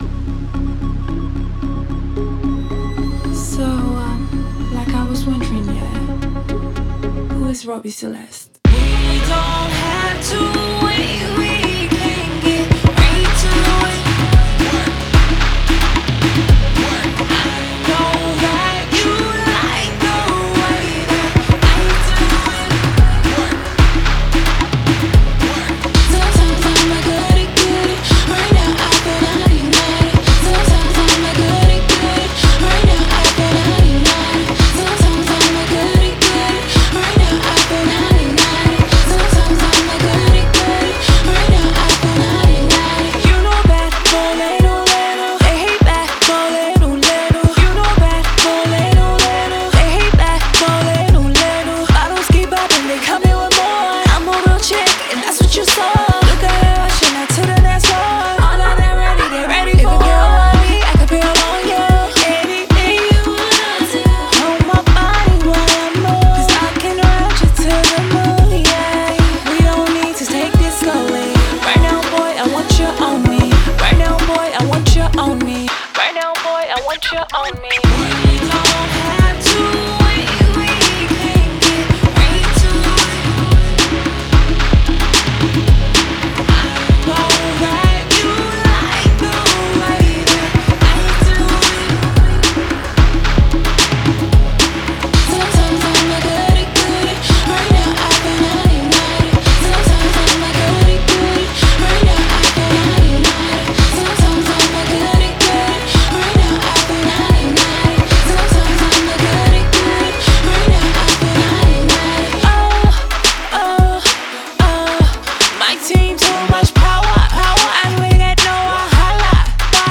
Afro Pop/Dance single